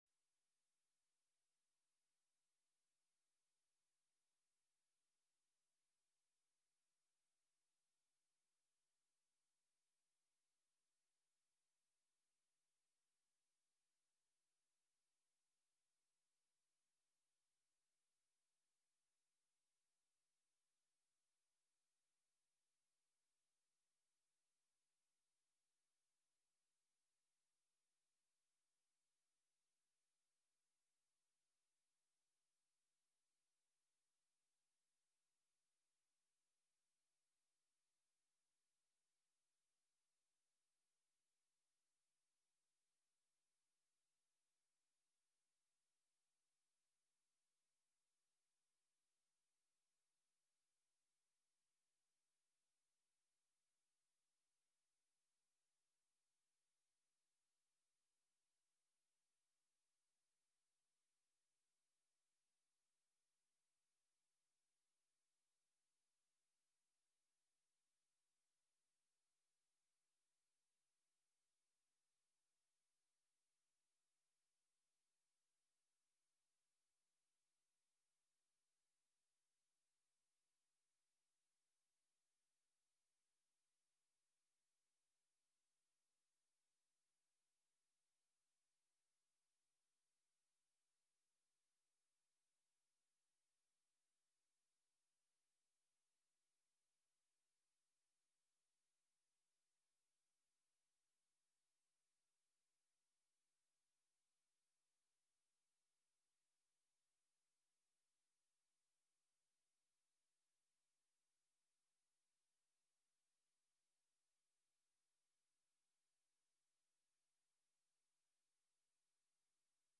broadcasts live with music, call-ins, news, announcements, and interviews